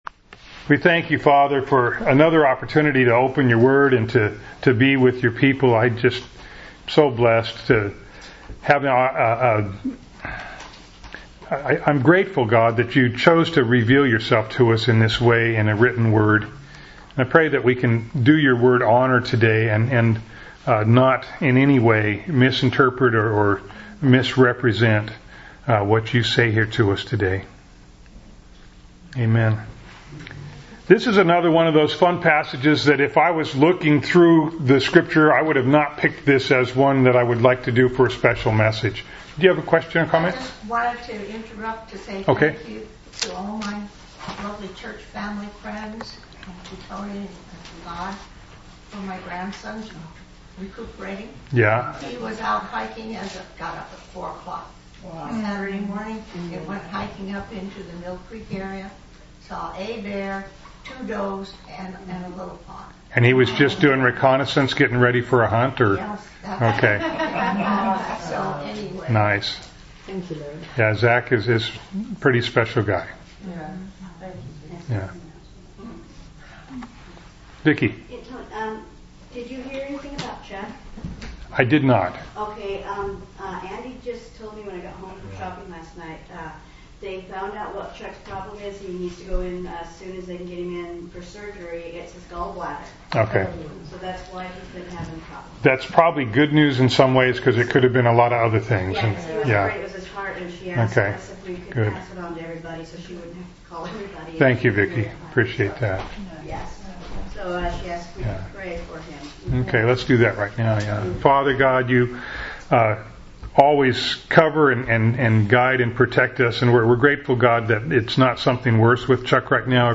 Grace To The Barren – Skykomish Community Church